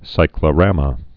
(sīklə-rămə, -rämə)